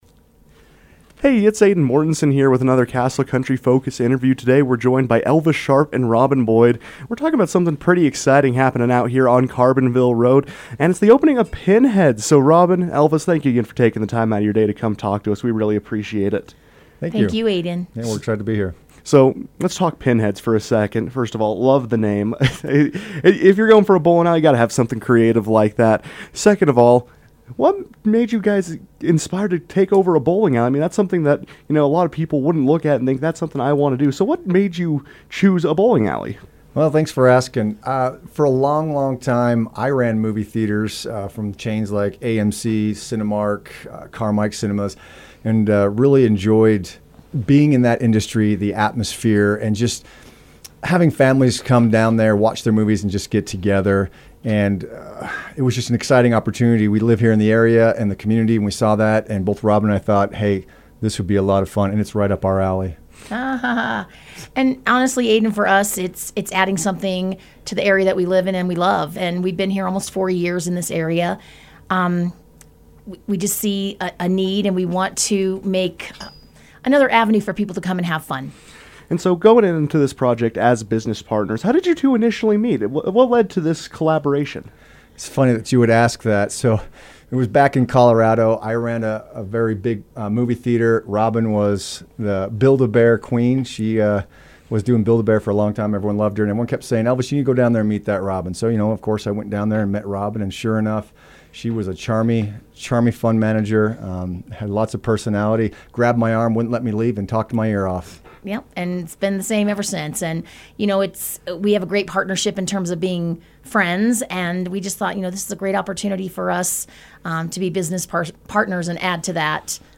joined the KOAL newsroom to explain the genesis of their partnership, the opening process, and what's coming up next for Pin Heads.